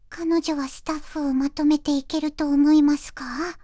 voicevox-voice-corpus / ita-corpus /中国うさぎ_こわがり /EMOTION100_028.wav